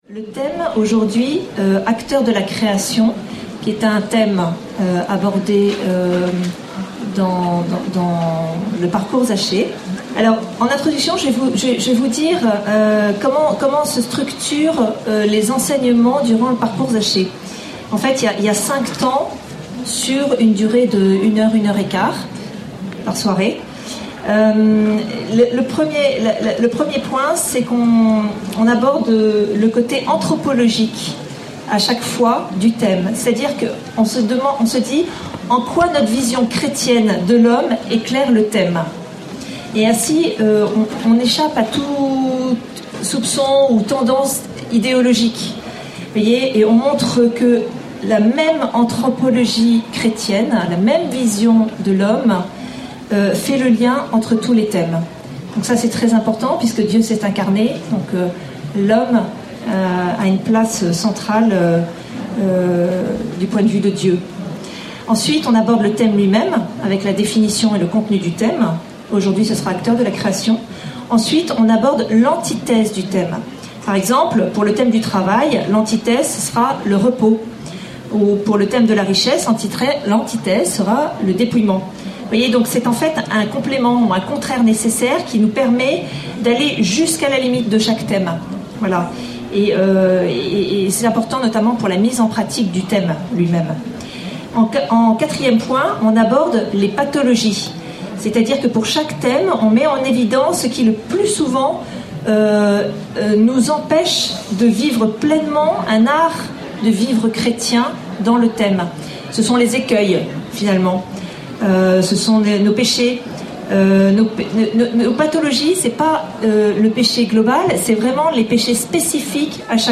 Enseignement
Session des Familles et pour tous
Paray-le-Monial, du 3 au 8 août 2013